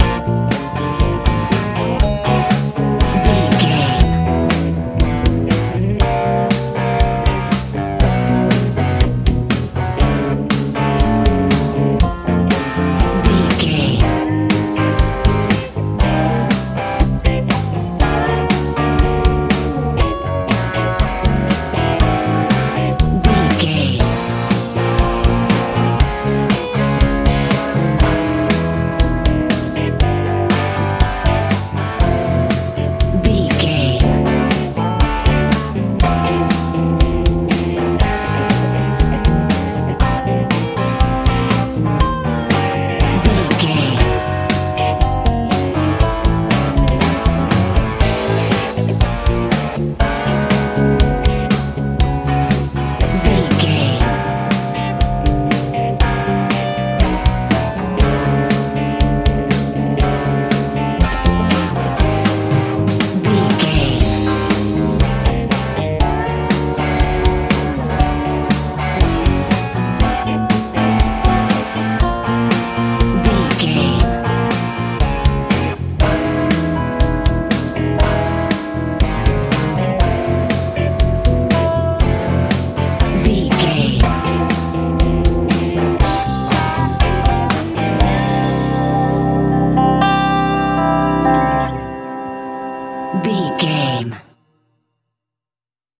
texas blues rock music
Ionian/Major
energetic
powerful
electric guitar
bass guitar
drums
lively
joyful
driving